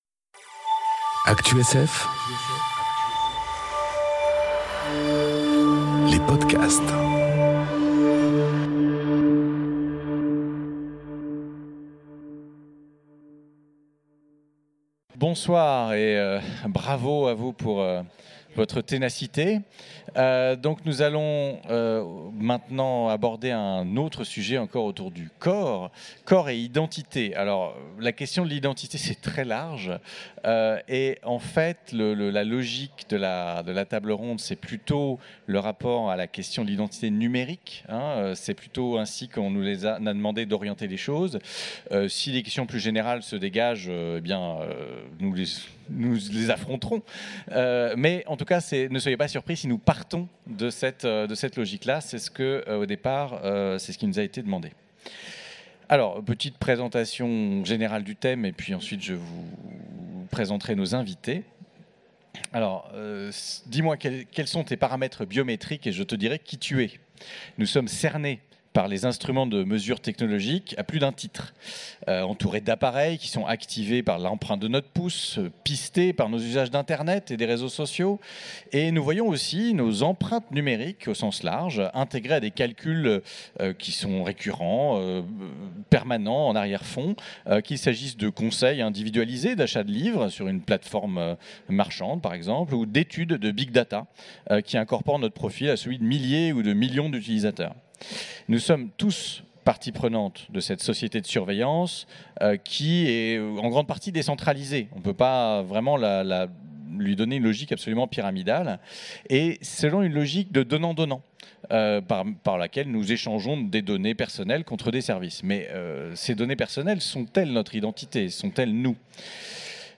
Conférence Corps et identité enregistrée aux Utopiales 2018